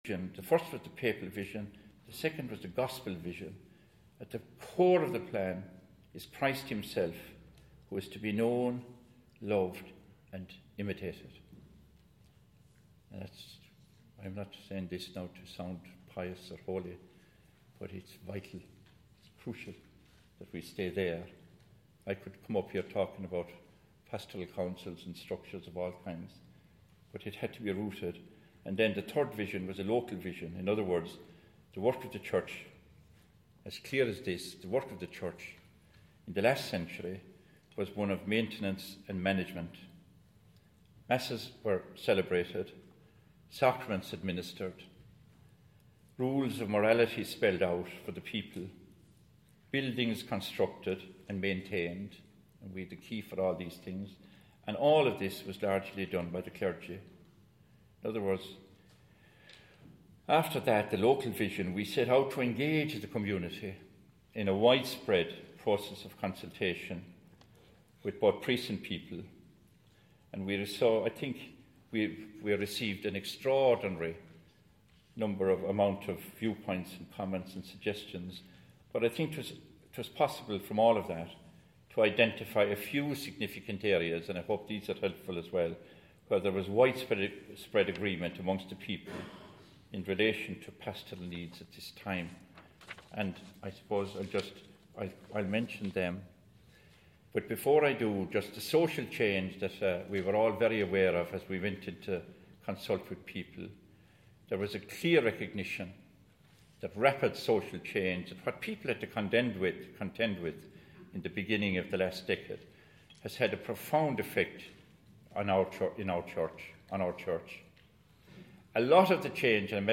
a first step" during Meath diocesan Assembly 24 April 2012